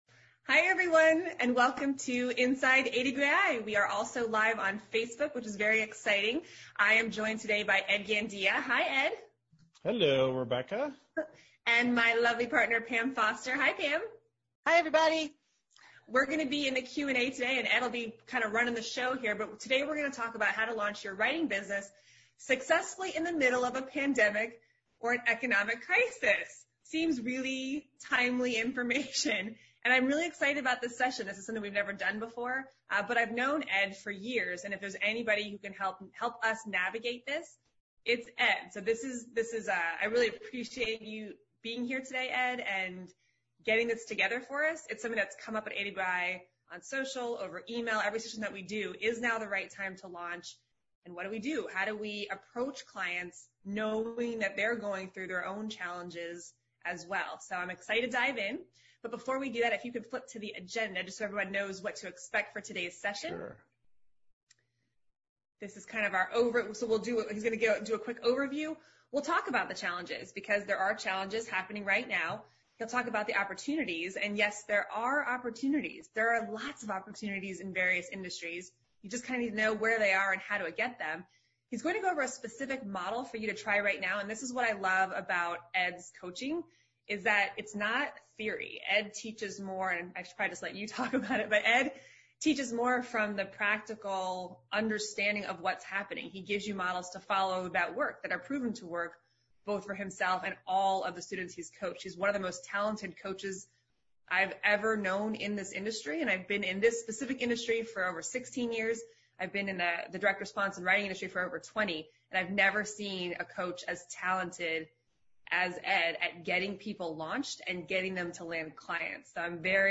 Inside AWAI Webinar and Q&A: How to Launch Your Freelance Writing Business During a Pandemic or Economic Crisis
They also opened up the lines at the end for a live Q&A with listeners.